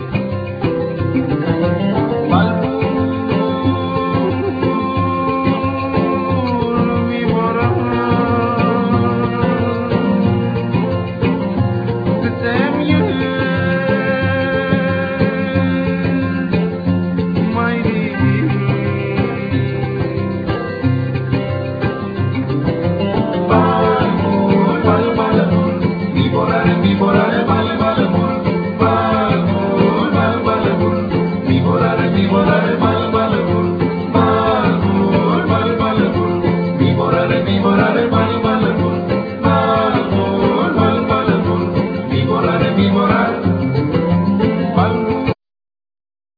Vocal,Sazabo,Duduk,Percussion
Oud,Cumbus,Sazbus,Keyboards